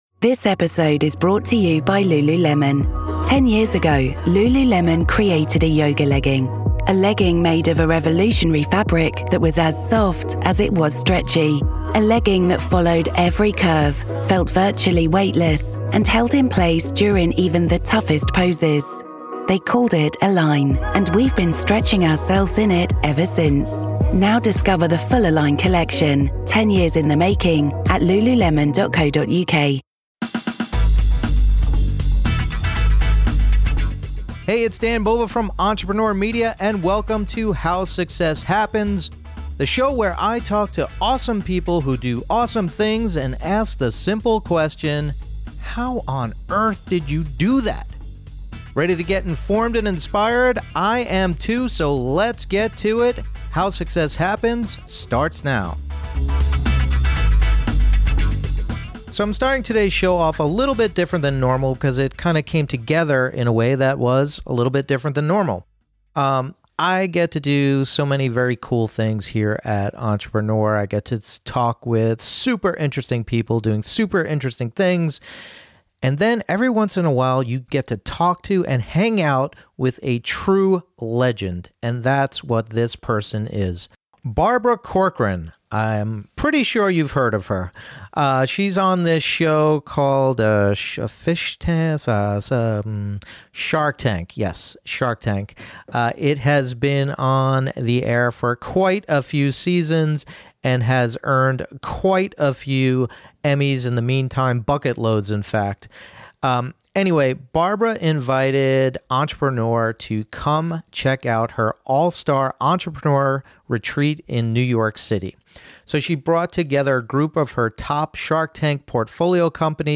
In this candid conversation, Barbara talks about how to realistically find your passion, how she learned to tune out negativity, and which Shark she thinks is the cheapest.